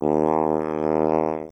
Voice Lines
OOooOooOOO Drug fiend.wav